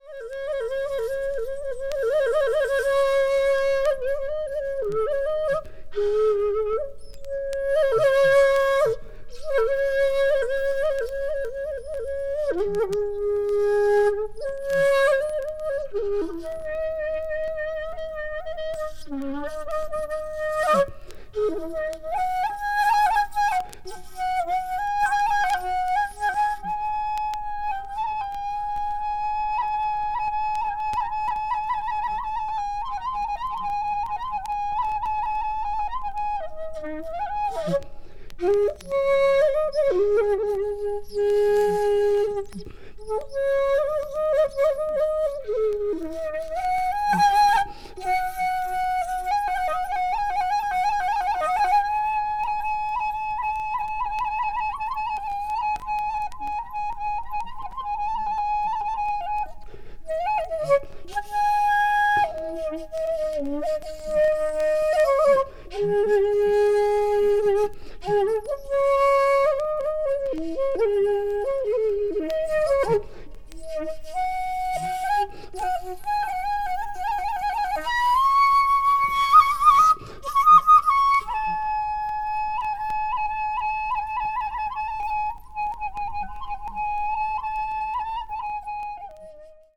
media : EX-/EX-(some light noises.)
bamboo flute